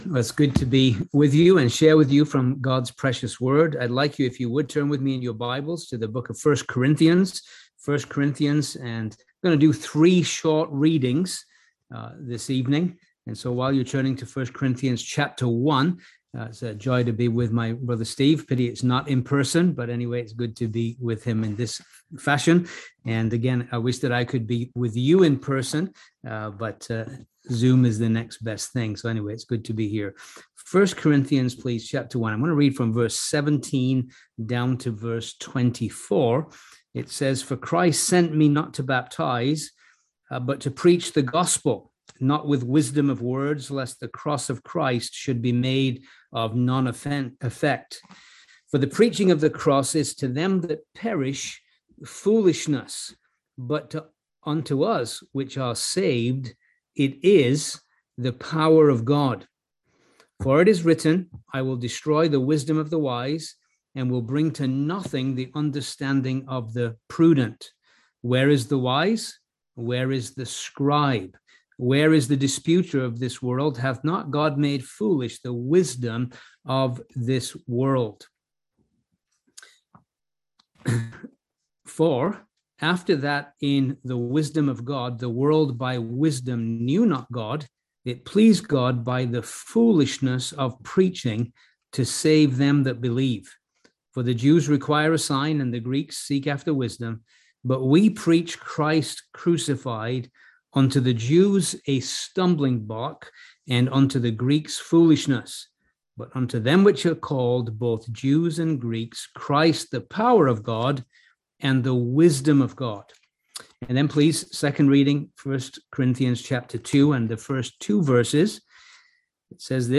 Series: Easter Conference
Service Type: Seminar